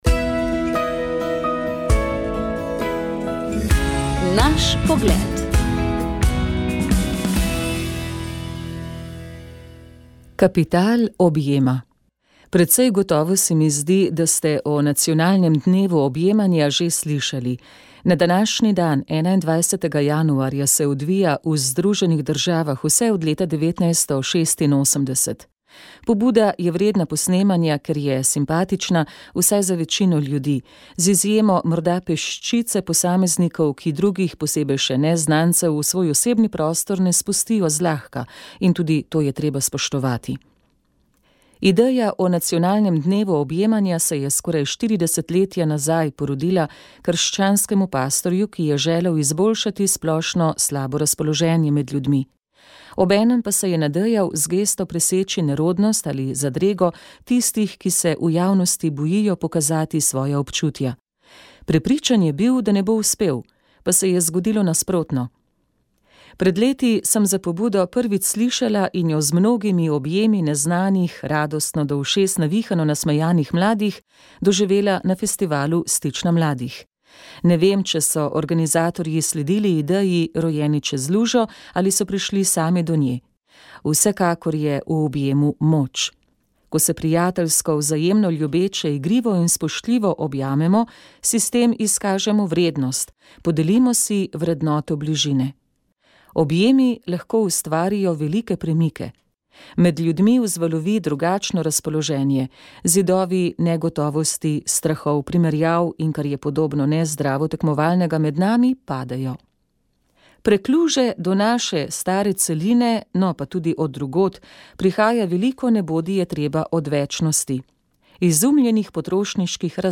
komentar družba politika koronavirus